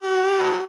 moan1.wav